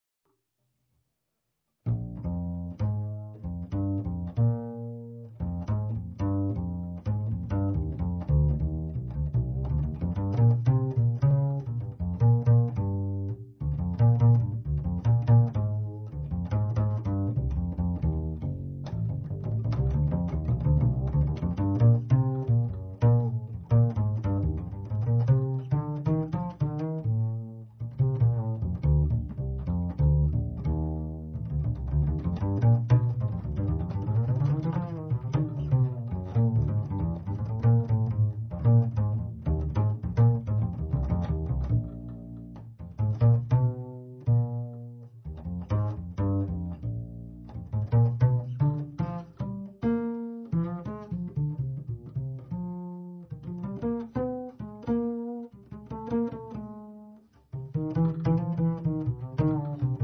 Decisamente accattivante è il blues